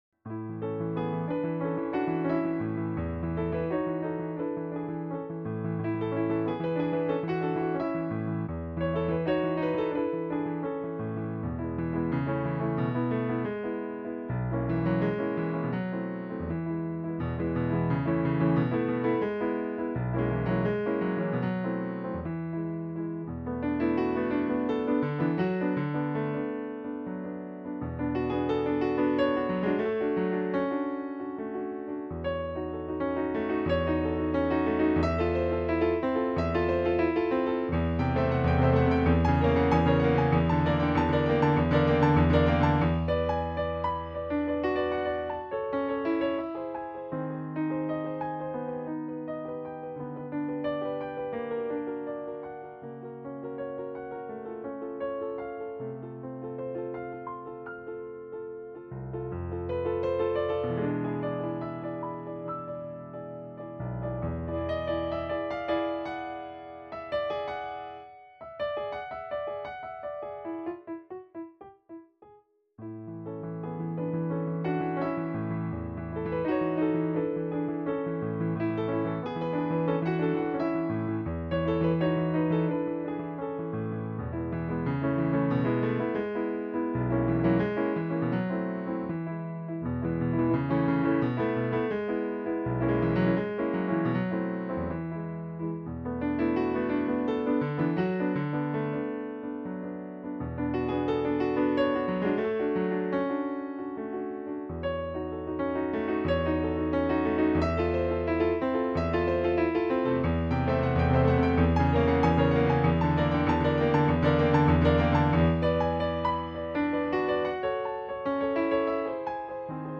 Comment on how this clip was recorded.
at his piano at home in 2024